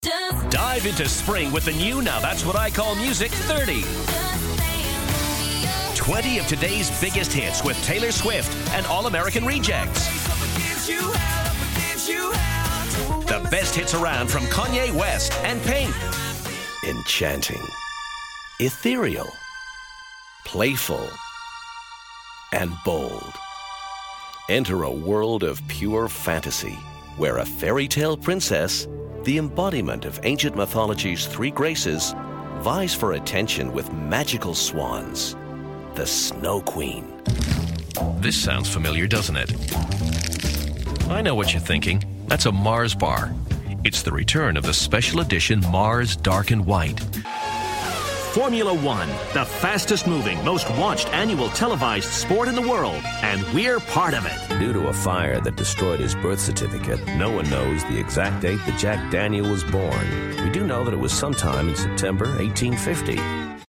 David Jensen - Adverts - Megamix 1
Male, 40s, 50s, 60s, American, DJ, The Kid, commercial, advert, voiceover, voice over, DGV, Damn Good Voices, damngoodvoices, Crying Out Loud, cryingoutloud,